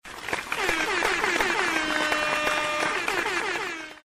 ClapHorn
claphorn.mp3